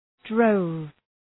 {drəʋv}